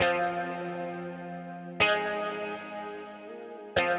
Watching (Clank Git) 120BPM.wav